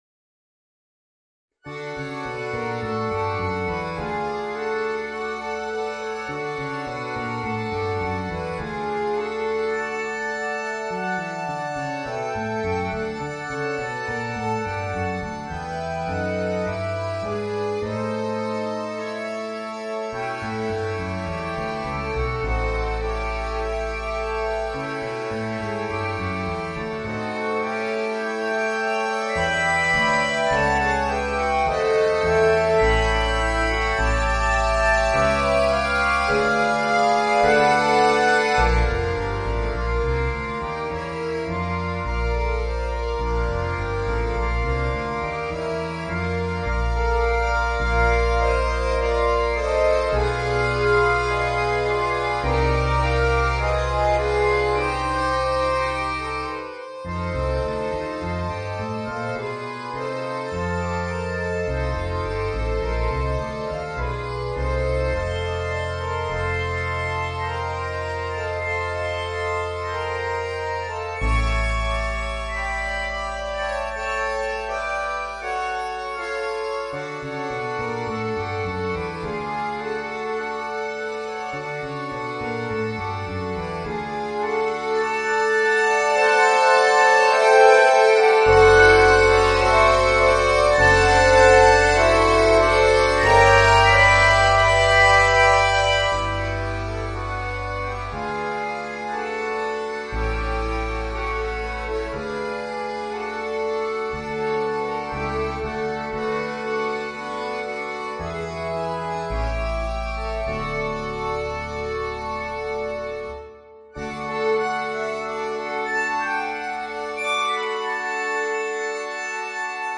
Voicing: Accordion Ensemble